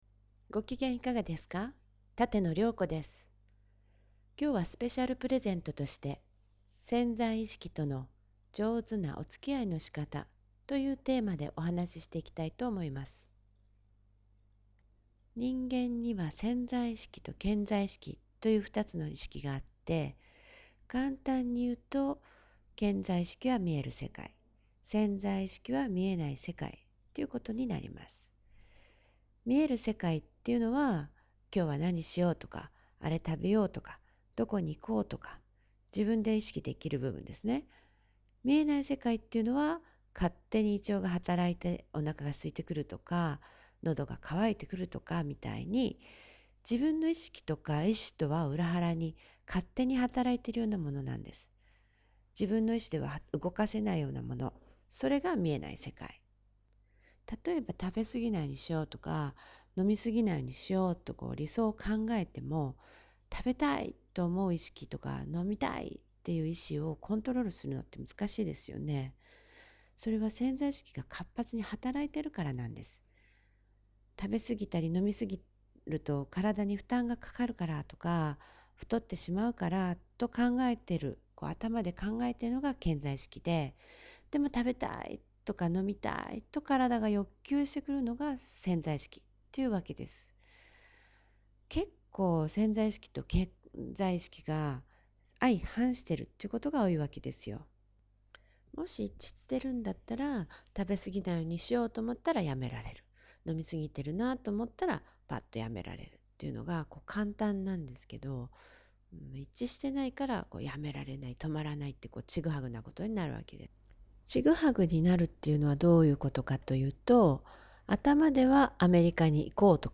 2011 ジャンル: voice